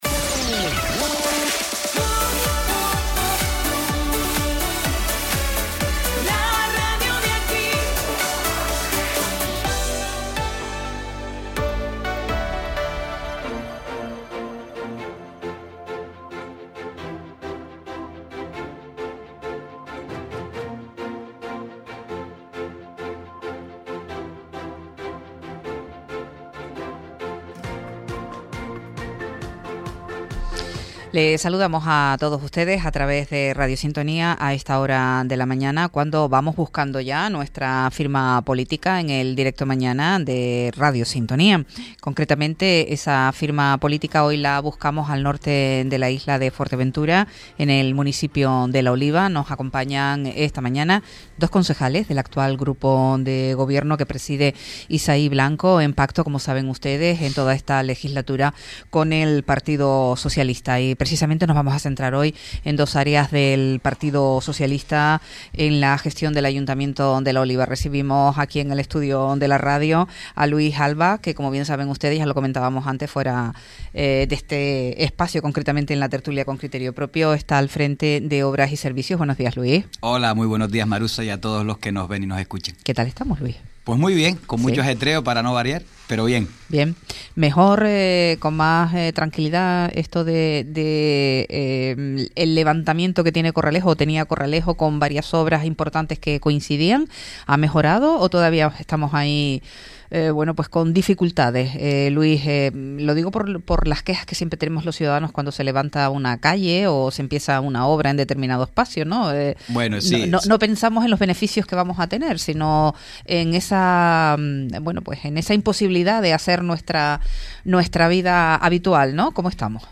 Hoy firma nuestro apartado de política en la radio, el concejal de Obras y Servicios en La Oliva, Luis Alba y el concejal de Tráfico, Salud Pública, Parques y Zonas de Ocio, Christian Rodríguez - 05.03.26 - Radio Sintonía
Entrevistas